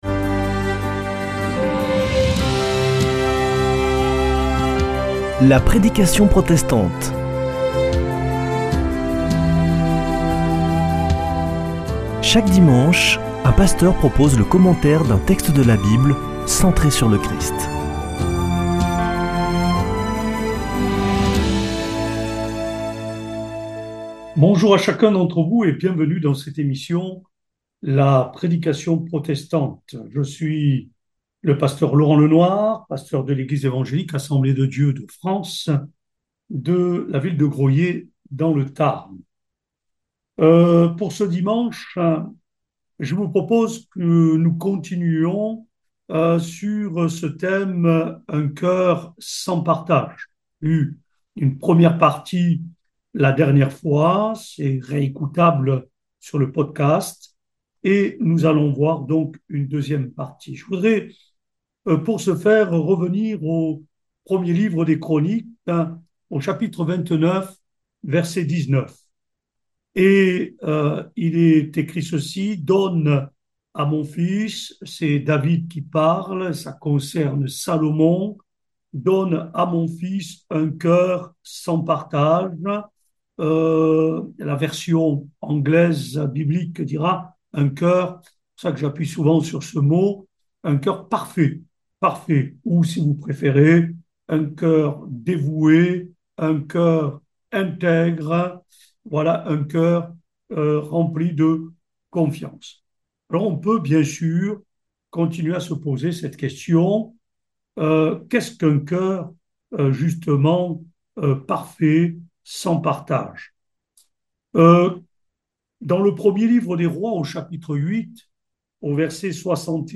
Accueil \ Emissions \ Foi \ Formation \ La prédication protestante \ Un coeur exemplaire 2ème partie : "un coeur sans partage."